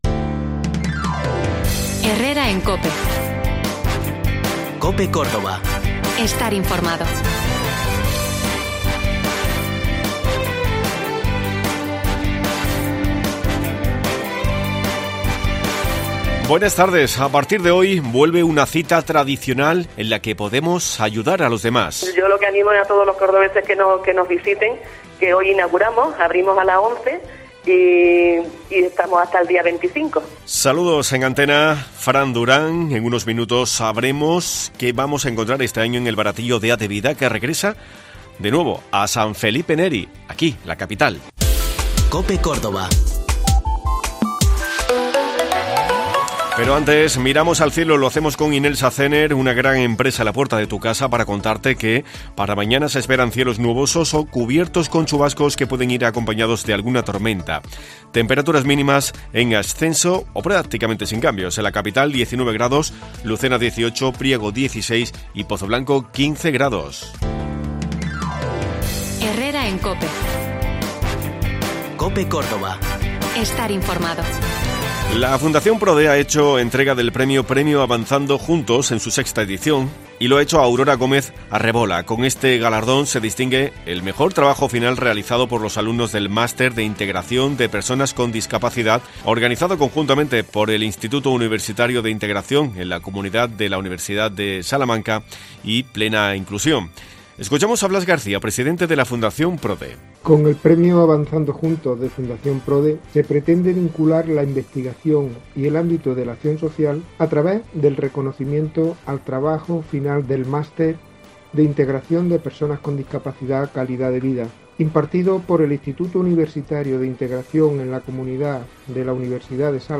Además conocemos el pronóstico del tiempo con vistas al fin de semana y otras noticias de este viernes 19 de noviembre LA ACTUALIDAD CADA DÍA Te contamos las últimas noticias de Córdoba y provincia con los reportajes que más te interesan y las mejores entrevistas.